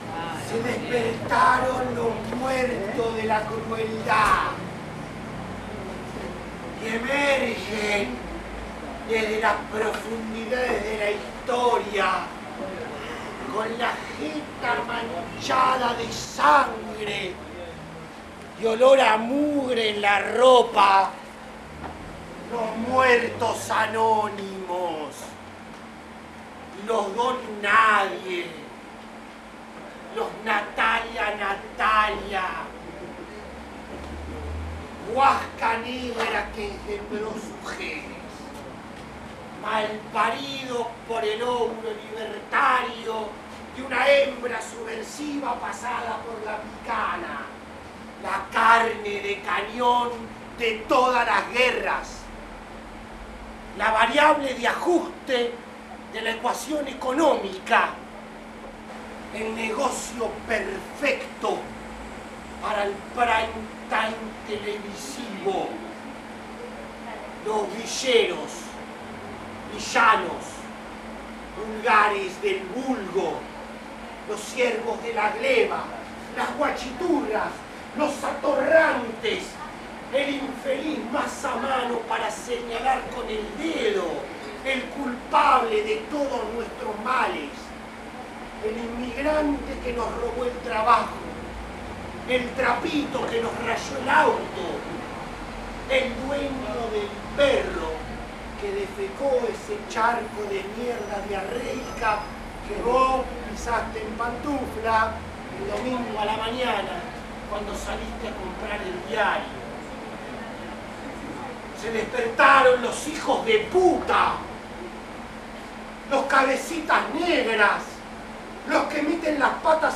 EL fonografista se detiene ante el hecho y registra. Espera que algo suceda, que algo sonoro suceda; eso es lo atractivo y lo emocionante de estar al acecho.